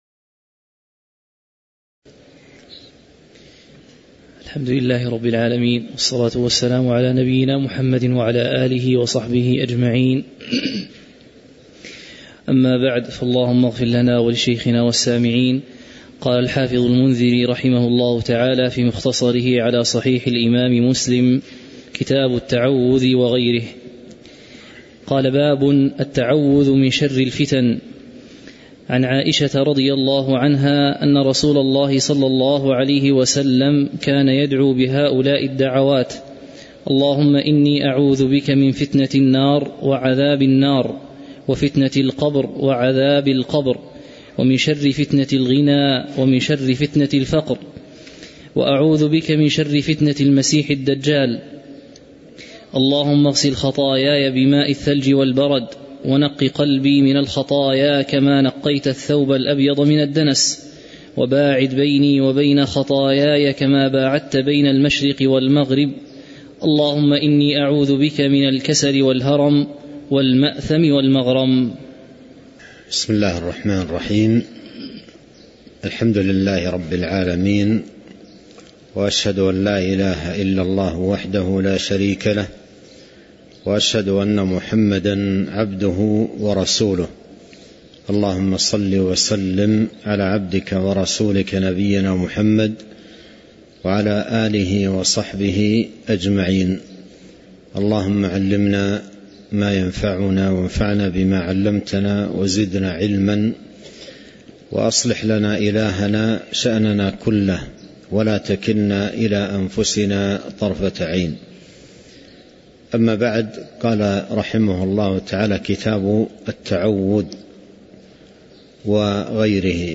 تاريخ النشر ٧ ذو الحجة ١٤٤٣ هـ المكان: المسجد النبوي الشيخ